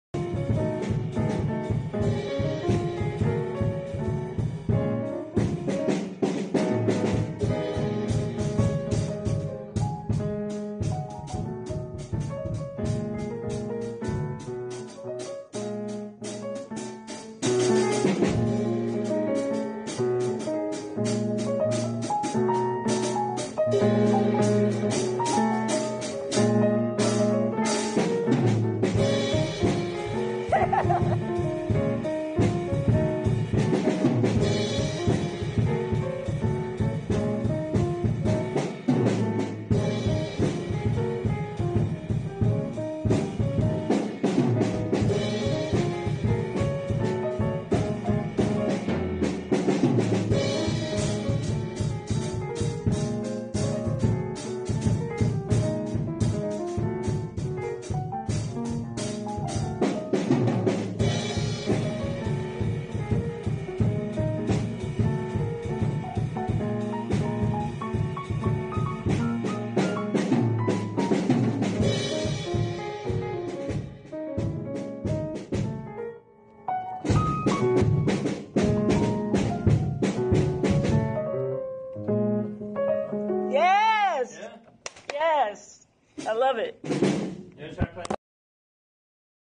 playing with other music students